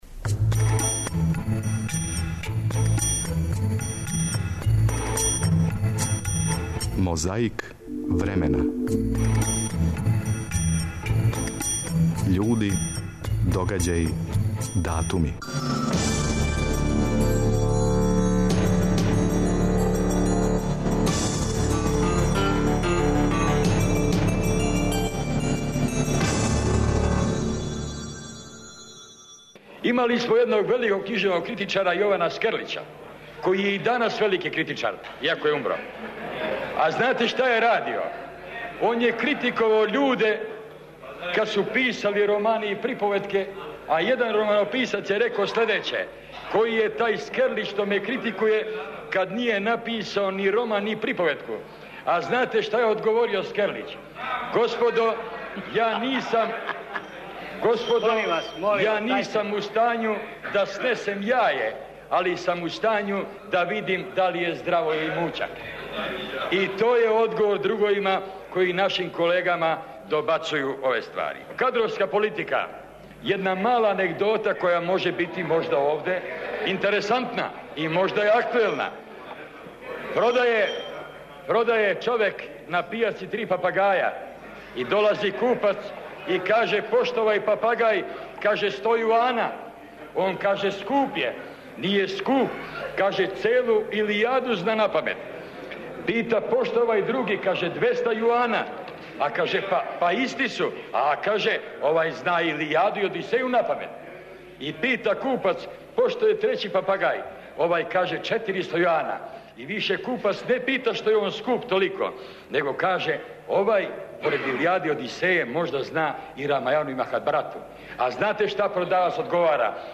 На почетку овонедељне борбе против пилећег памћења емитујемо анегдоте изговорене пред микрофоном Скупштине СРЈ. Сценски наступ је имао посланик Живорад Игић 28. новембра 2000. године.